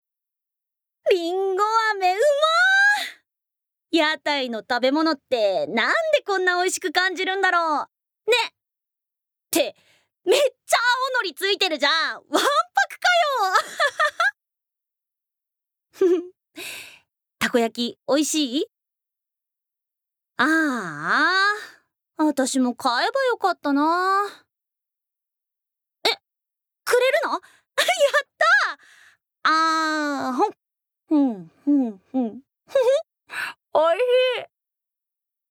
Voice Sample
ボイスサンプル
セリフ３